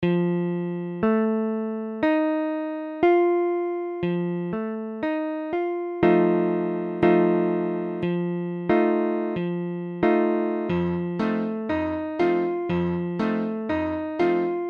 F7 : accord de Fa septi�me Mesure : 4/4
Tempo : 1/4=60